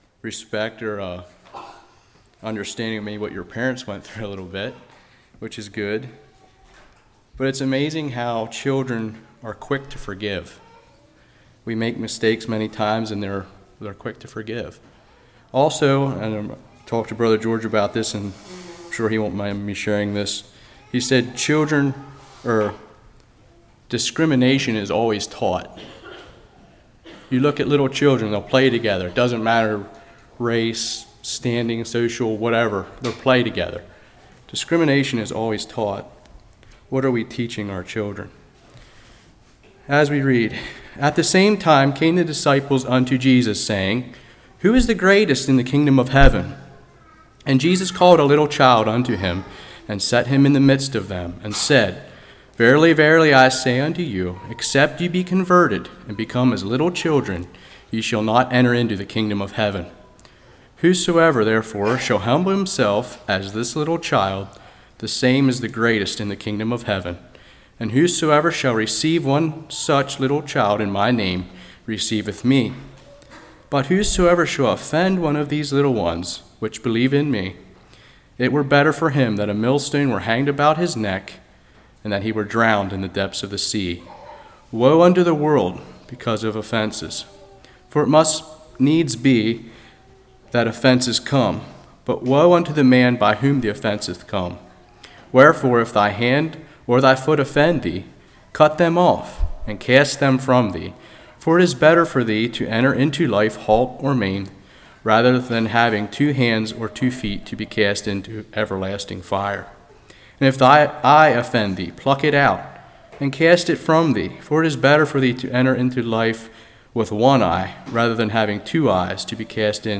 Service Type: Revival